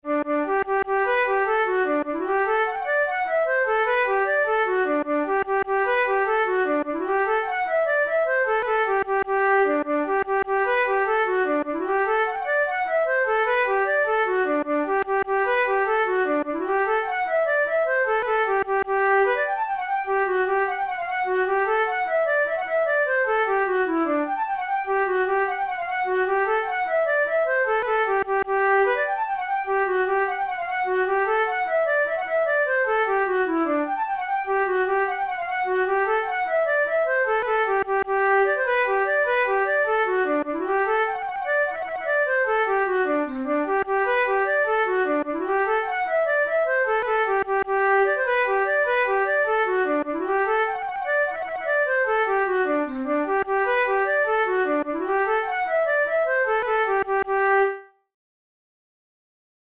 InstrumentationFlute solo
KeyG major
Time signature6/8
Tempo100 BPM
Jigs, Traditional/Folk
Traditional Irish jig
This lively jig appears to be unique to Francis O'Neill's collection The Dance Music Of Ireland, published in Chicago in 1907.